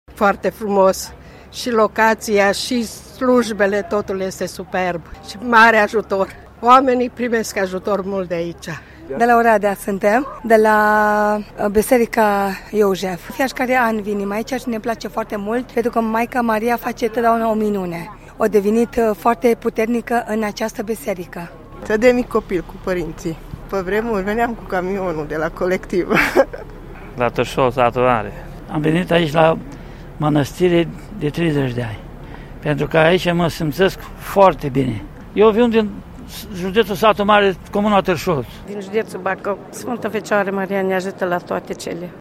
05-voxuri-Radna-de-unde-vin.mp3